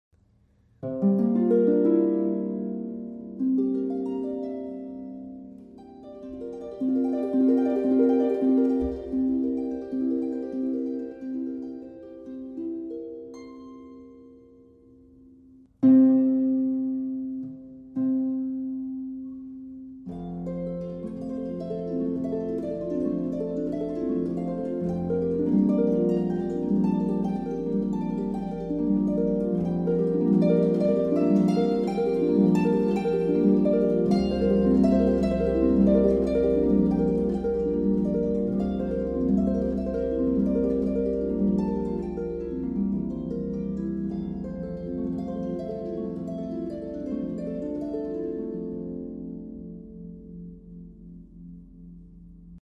Midlands Based Harpist For Hire
• Mixed repertoire and amplification options available